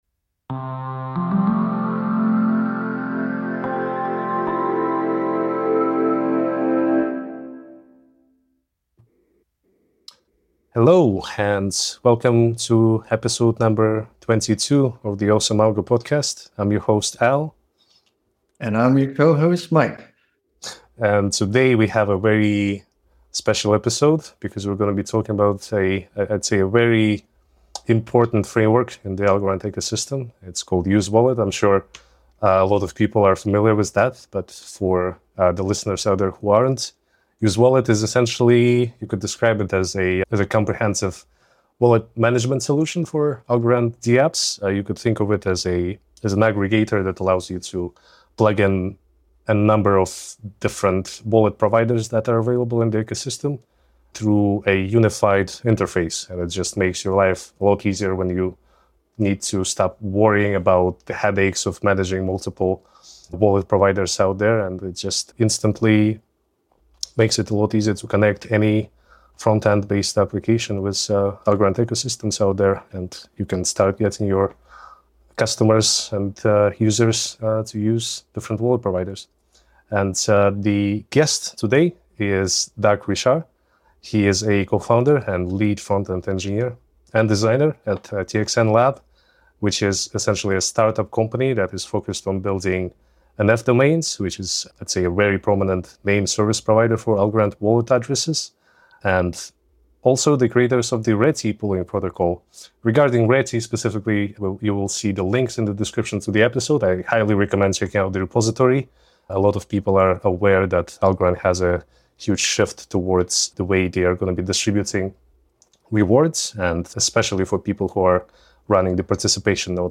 The interview covers the development history, moving from React dependency to a more flexible, framework-agnostic solution, and integrating different wallets including the new Defly web extension.